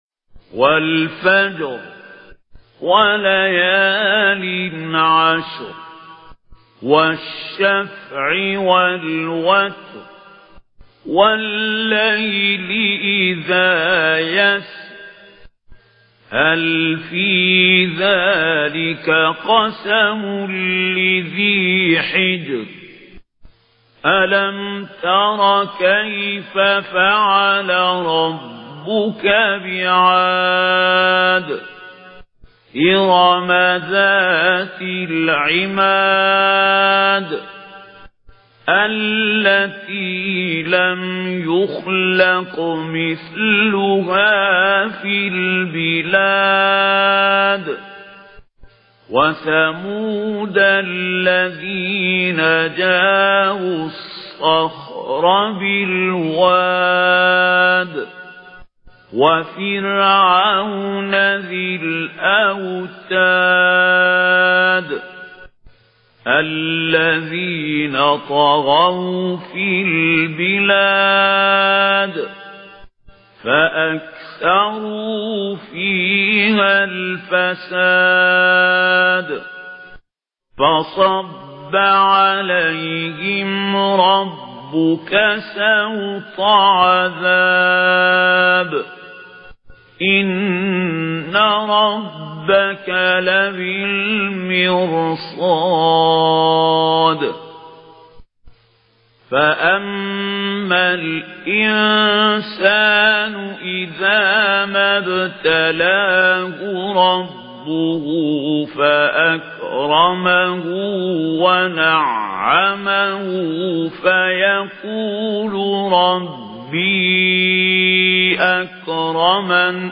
Récitation par Mahmoud Khalil Al Hussary